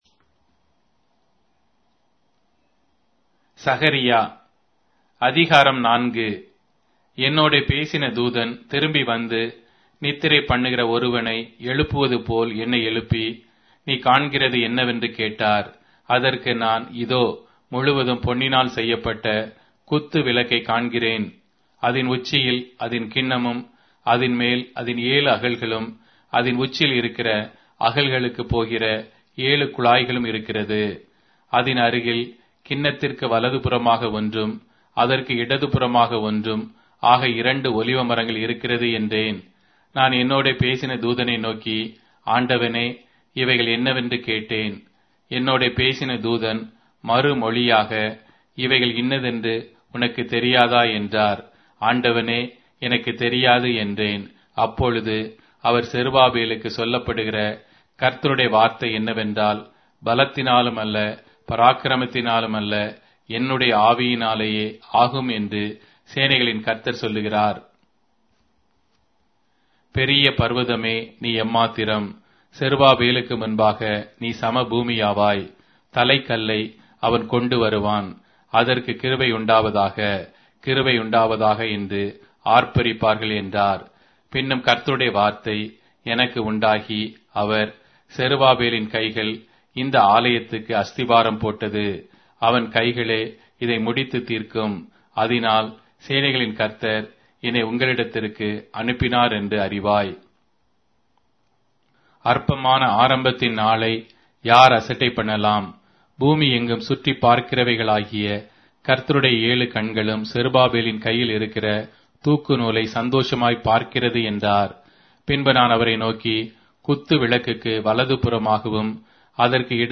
Tamil Audio Bible - Zechariah 7 in Mhb bible version